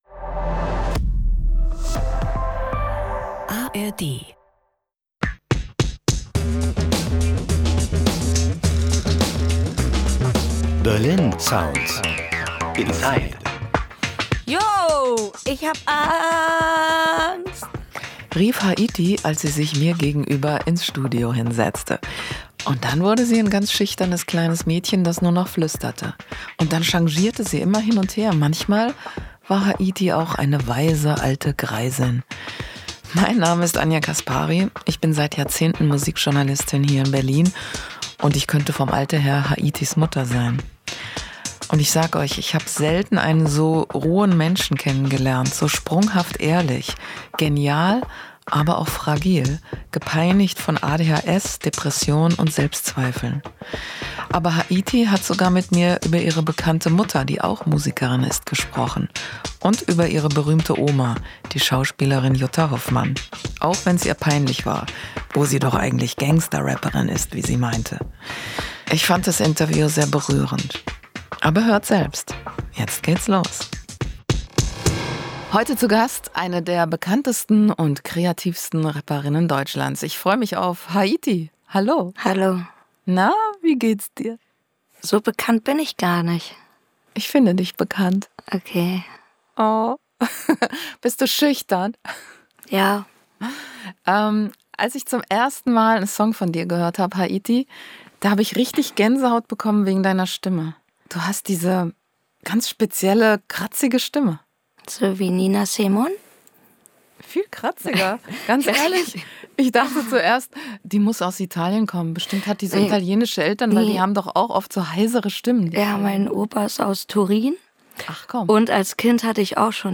Ein Gespräch über ADHS, unspontane Berliner, Depressionen und ein sehr wertvolles T-Shirt.